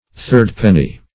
Meaning of third-penny. third-penny synonyms, pronunciation, spelling and more from Free Dictionary.
Search Result for " third-penny" : The Collaborative International Dictionary of English v.0.48: Third-penny \Third"-pen`ny\, n. (A.S. Law) A third part of the profits of fines and penalties imposed at the country court, which was among the perquisites enjoyed by the earl.